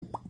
Click or tap the cat to make it pop and hear the iconic 'pop' sound.
pop.mp3